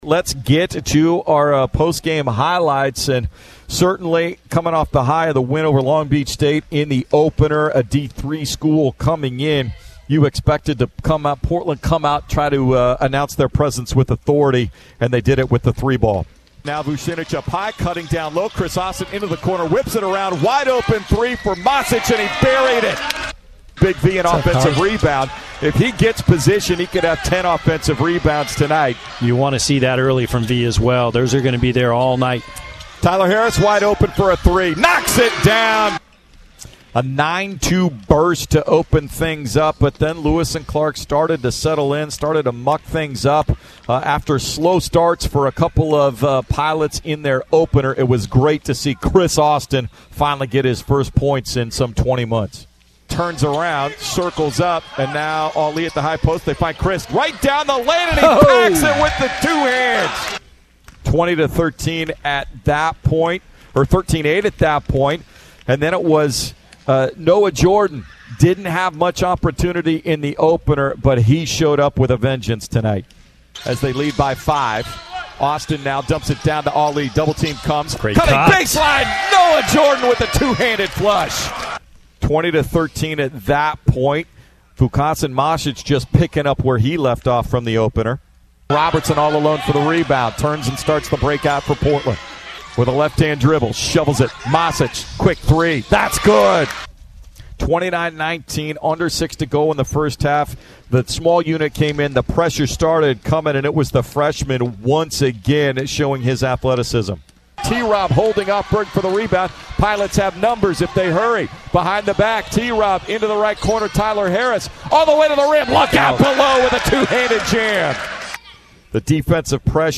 November 08, 2023 Radio highlights from Portland's 89-72 win against Lewis & Clark.